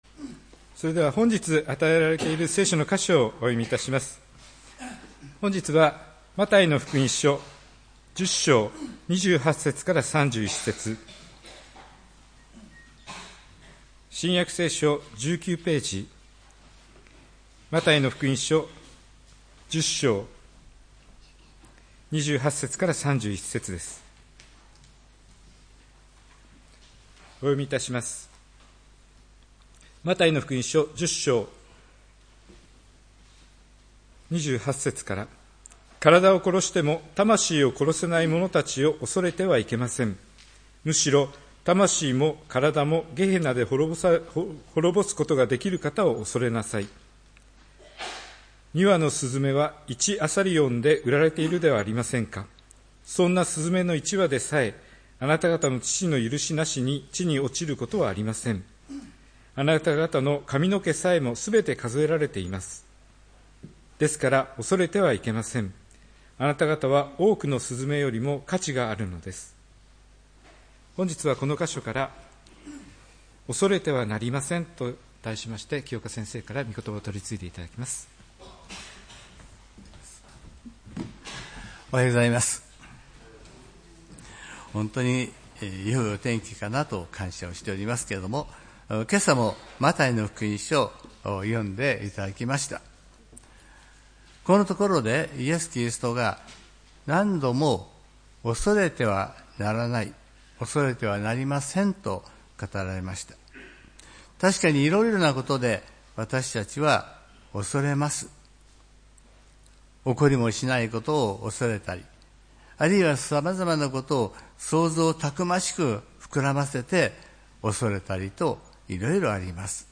礼拝メッセージ「恐れてはなりません」（４月19日）